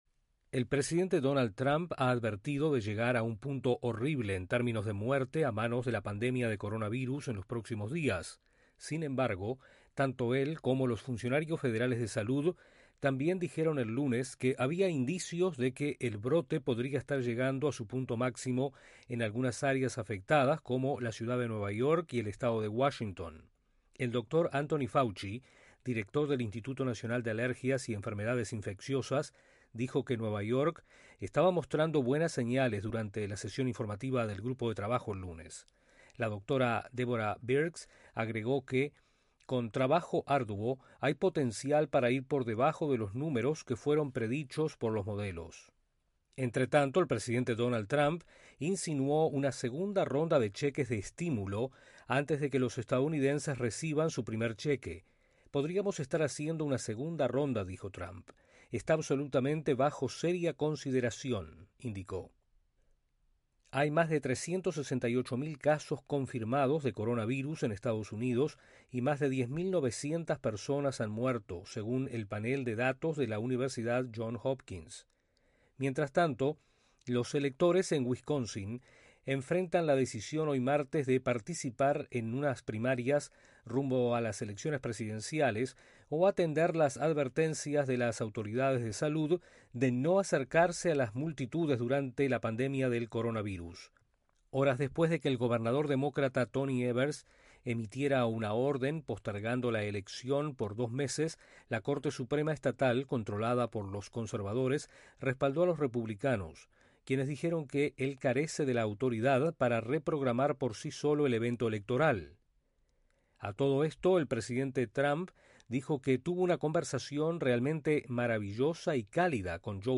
Autoridades de salud de EE.UU. destacan indicios de un alivio en los casos de COVID-19. Desde la Voz de América en Washington